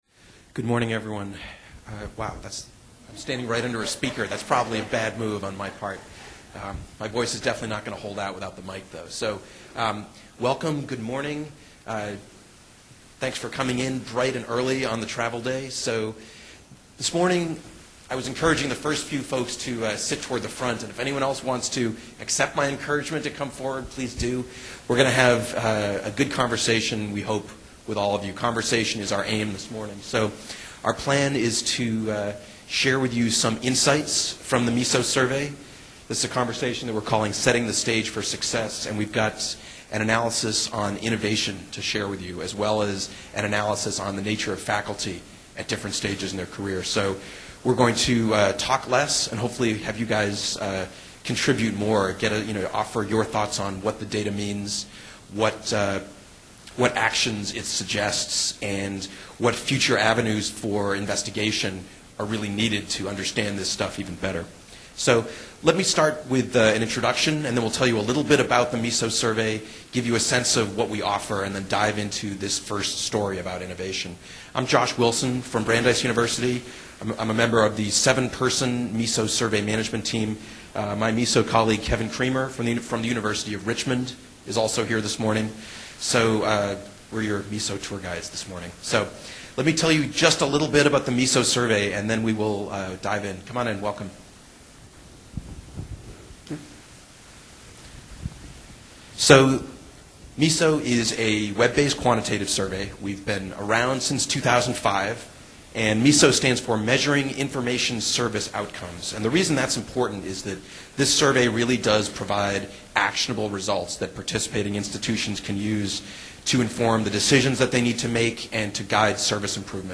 Delivered at the 2012 Annual Meeting of the EDUCAUSE Learning Initiative in Austin, TX.